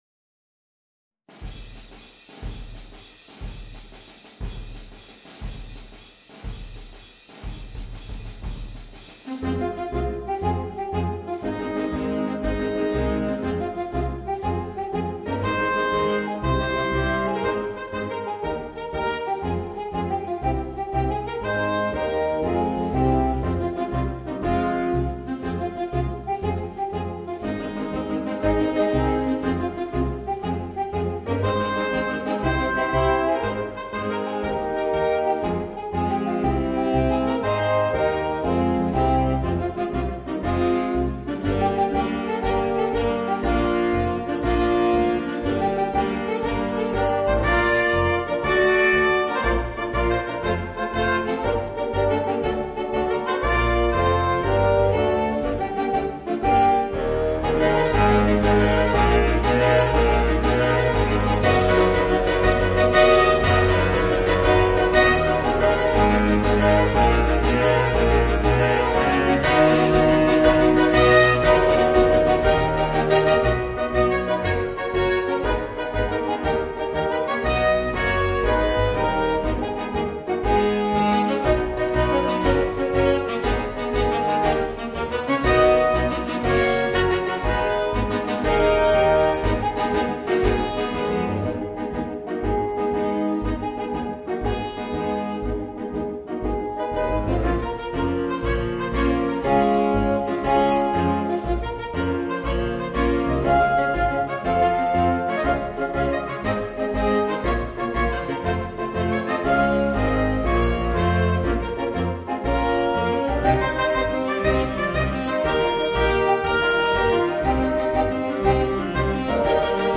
Voicing: 5 Saxophones